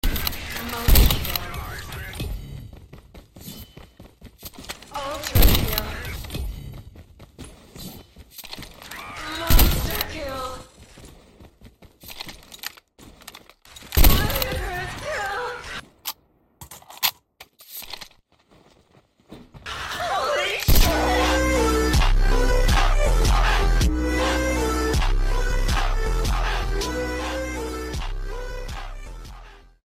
The new commanders voice in sound effects free download